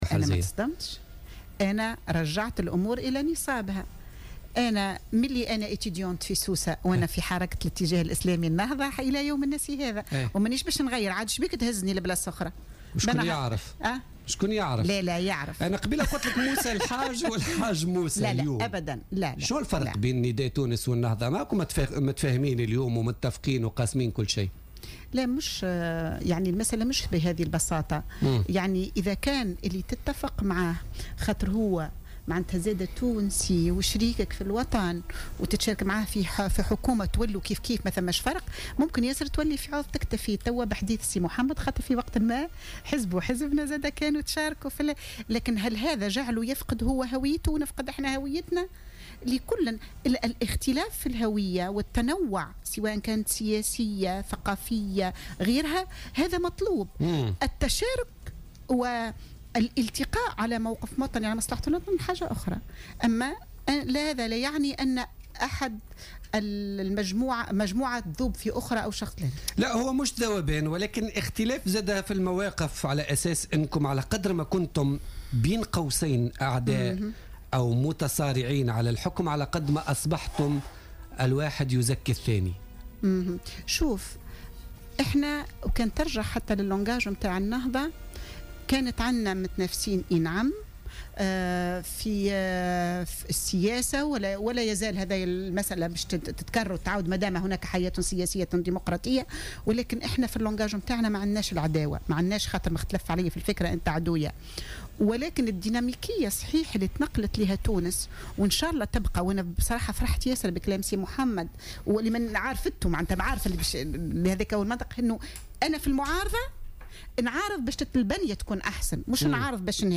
أكدت القيادية في حركة النهضة محرزية العبيدي ضيفة بوليتيكا اليوم الاثنين 14 ديسمبر 2015 ان انصهار النهضة وائتلافها مع حزب حركة نداء تونس لا يعني أننا فقدنا هويتنا موضحة أن الاختلاف في الهوية والخلفية السياسية والثقافية أمر مطلوب ولا يتعارض مع مبدأ الوحدة حول مصلحة تونس.